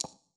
darkwatch/client/public/dice/sounds/surfaces/surface_felt7.mp3 at 3c3a92ce99b6d6fe10b7f1ab458a3a2b39ec9604
surface_felt7.mp3